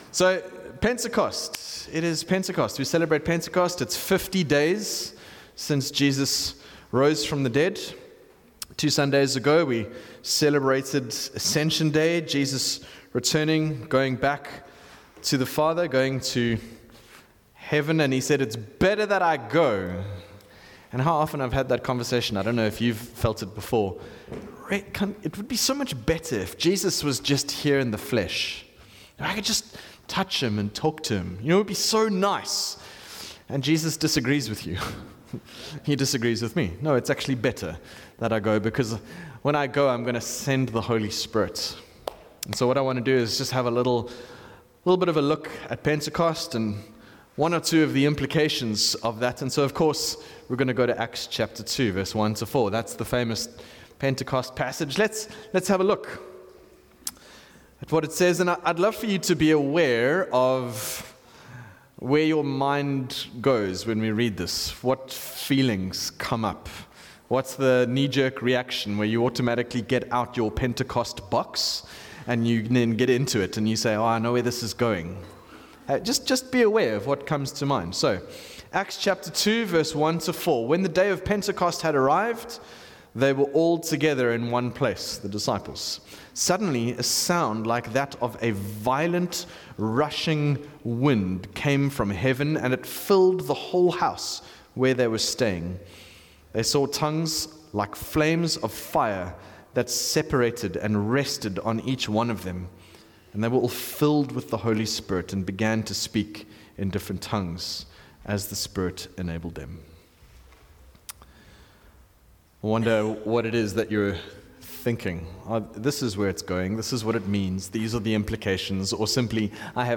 From Series: "Sunday Sermon"